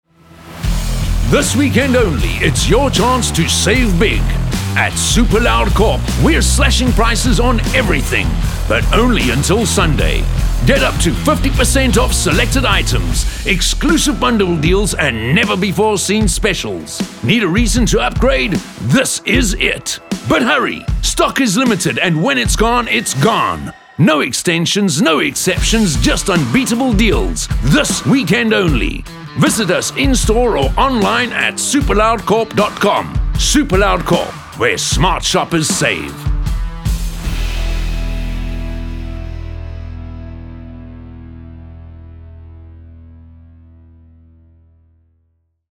articulate, authentic, captivating, confident, Deep, energetic, friendly
30-45, 45 - Above
Hard sell